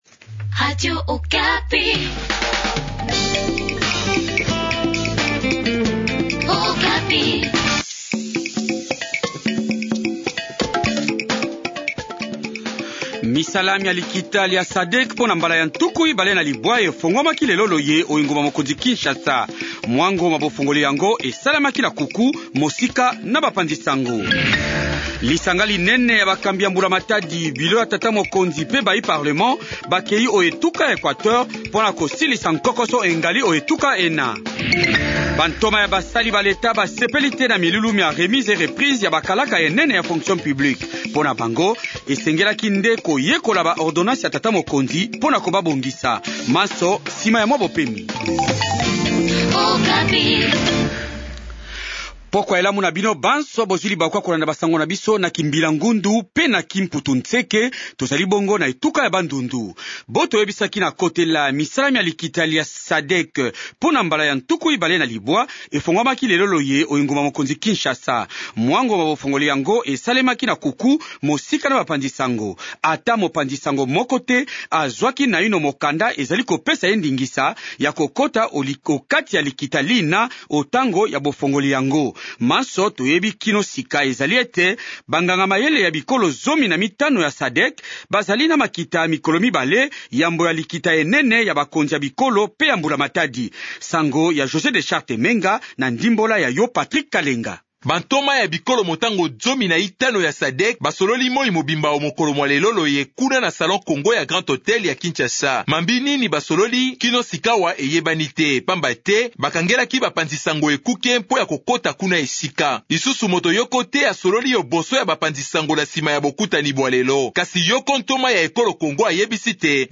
Journal Lingala soir